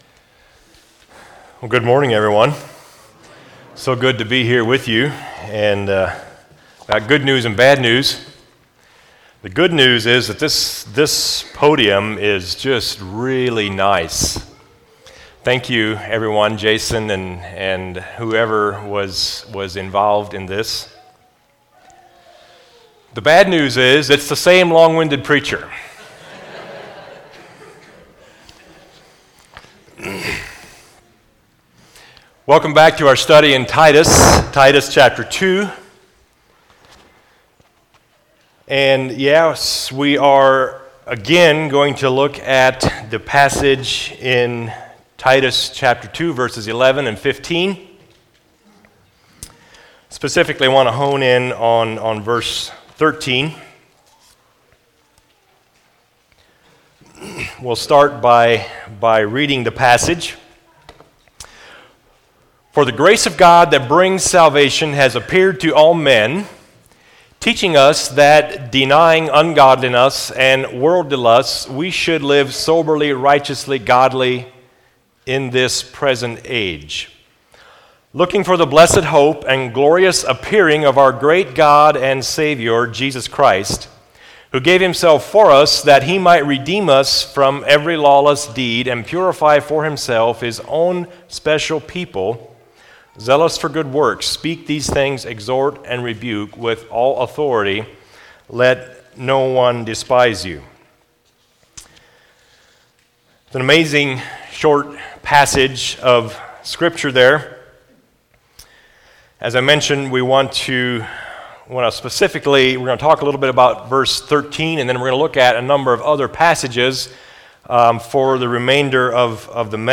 Home Sermons The Epistle of Titus Are You Near-Sighted or Far-Sighted?